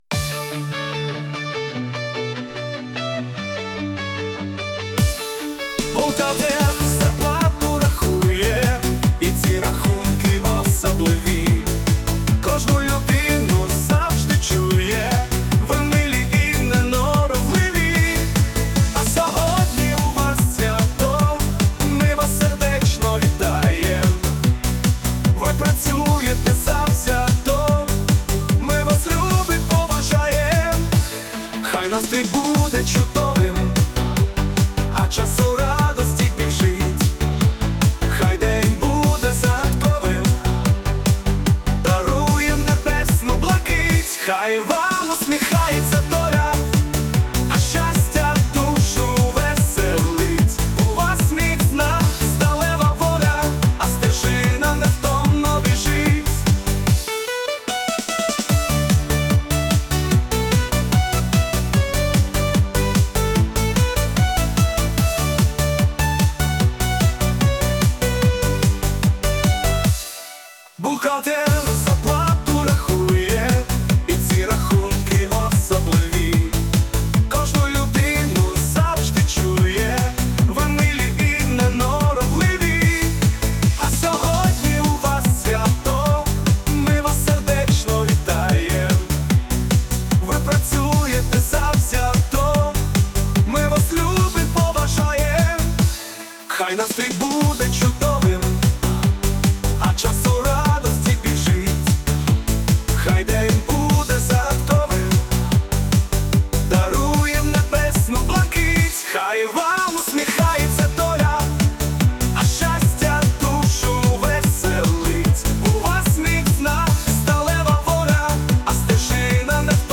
Бухгалтер (Пісня)